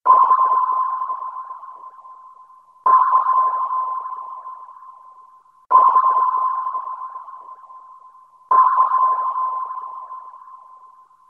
Звуки эхолота
Звук эхолота субмарины